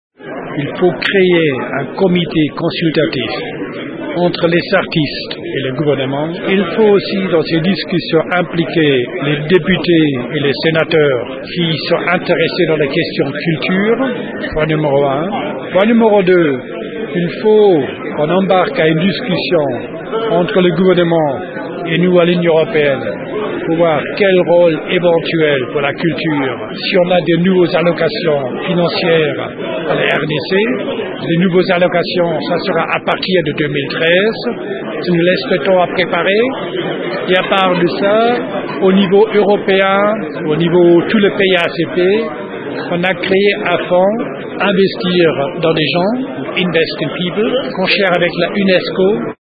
L’Ambassadeur Richard Zink, chef de la délégation de l’Union européenne le dit